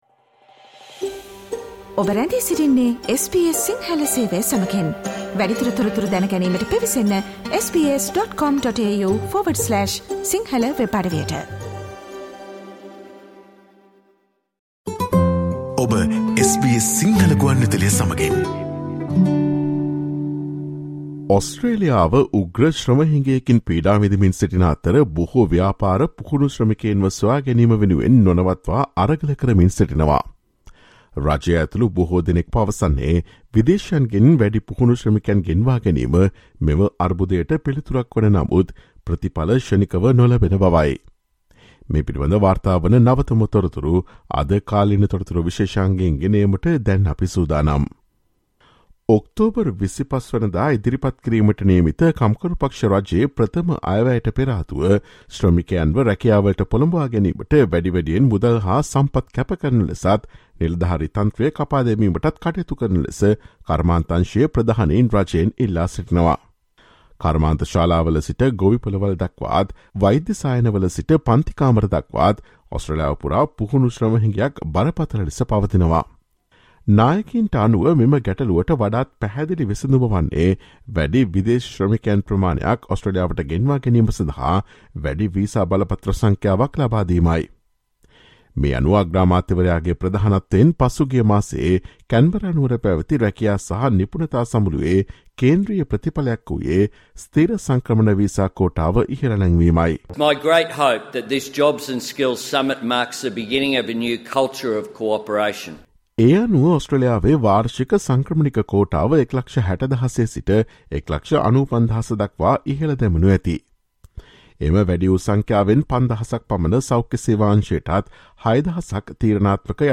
Australia is suffering through an acute labour shortage, leaving many businesses struggling to stay afloat. Listen to the SBS Sinhala Radio's current affairs feature broadcast on Thursday 20 October.